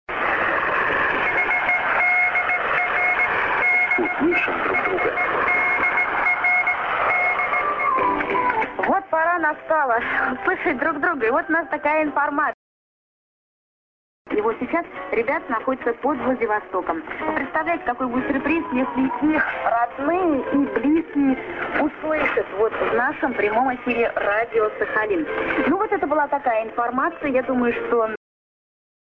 ->モールス信号->ID(women)->　USB R.Sakharinsk(Radio Rossii)